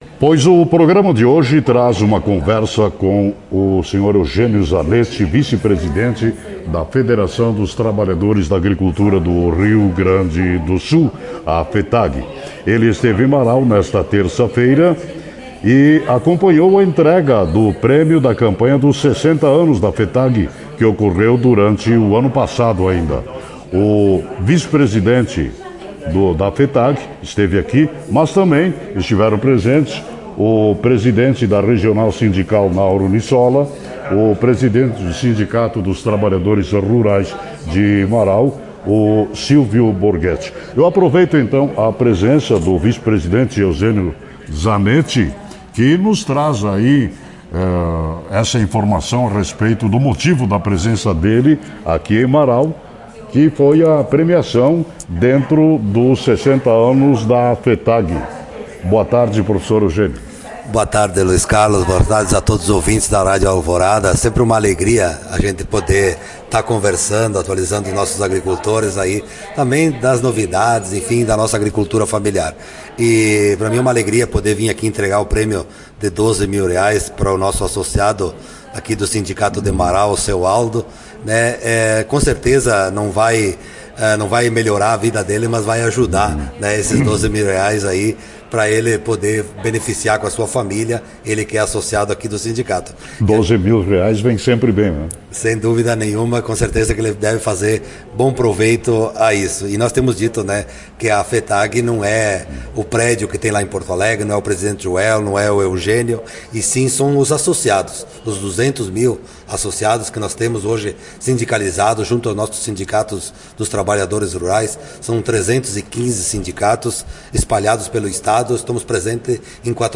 A entrevista, na íntegra, está disponível no site da emissora.